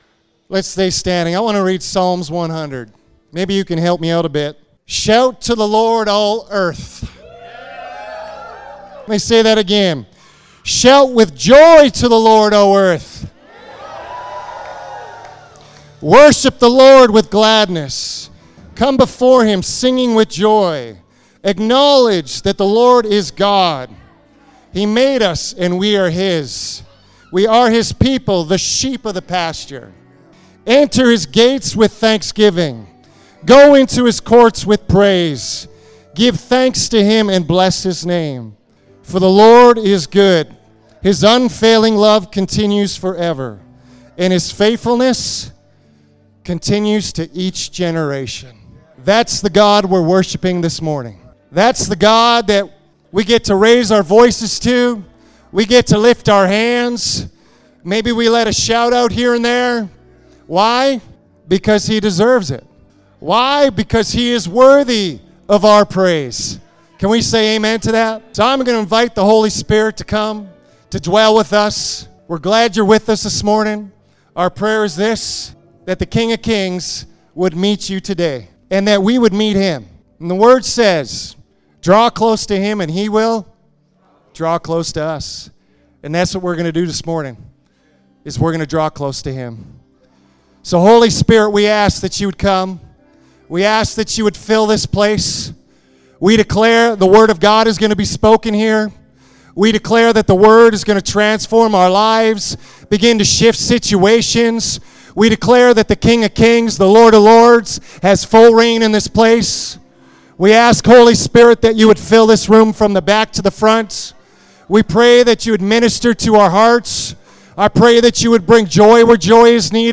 Service Audio from Community Church in Spruce Grove, Alberta, Canada